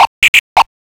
OLDRAVE 5 -L.wav